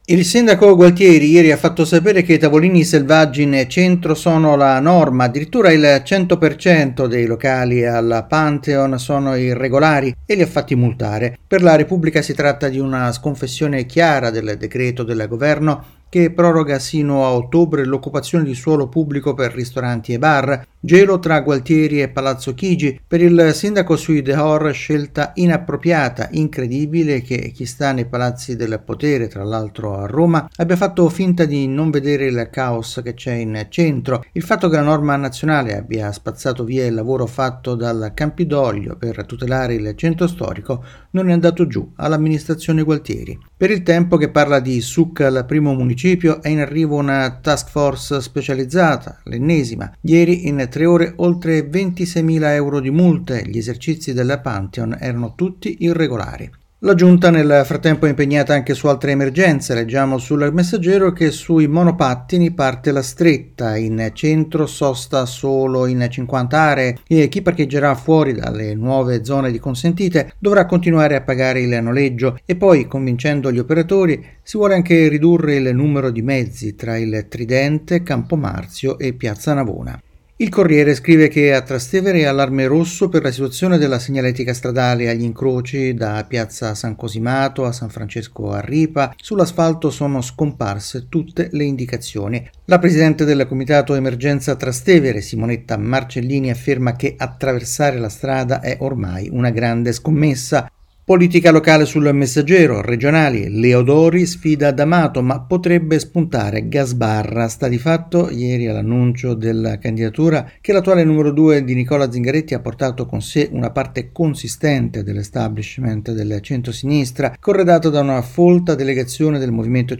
Ecco le principali notizie della giornata dalla rassegna stampa